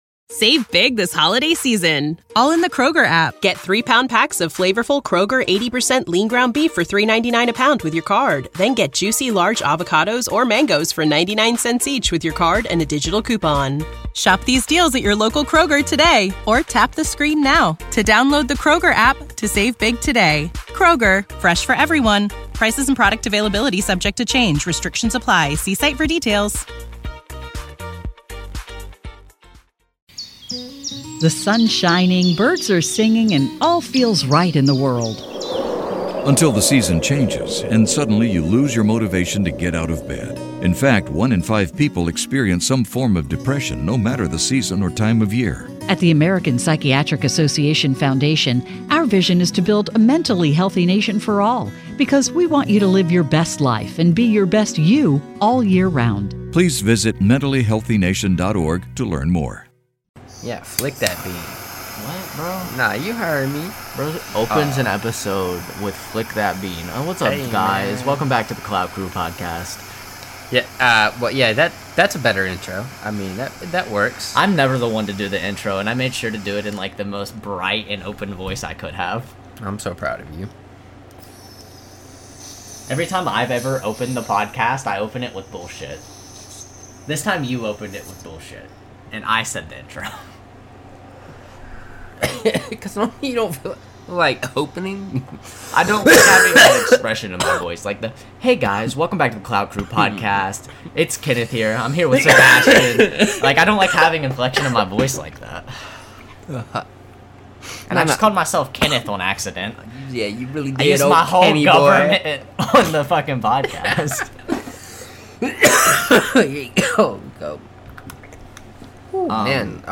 A laid back podcast that can involve many exciting game topics, sport opinions or wild debates, as well as so much more as we hope it brings entertainment to all of our listeners and viewers.